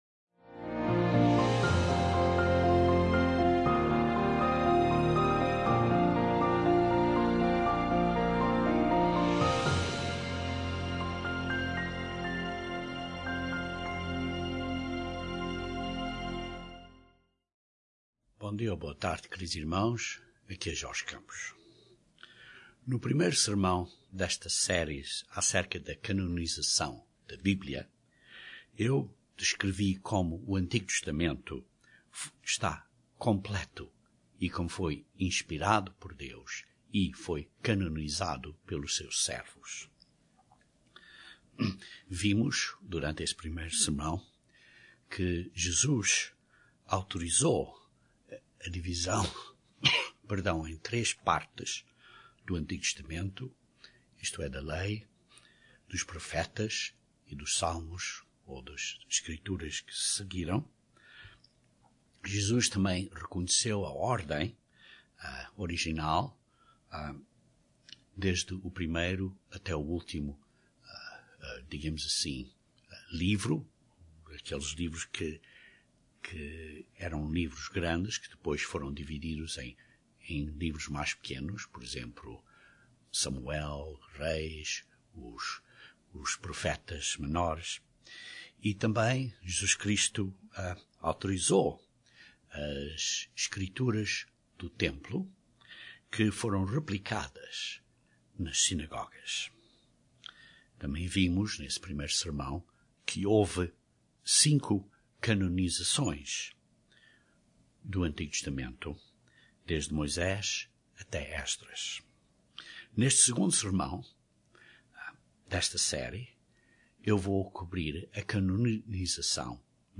Este sermão descreve e explica como Deus inspirou os apóstolos de Jesus Cristo, nomeadamente Pedro e João, a canonizar o Novo Testamento.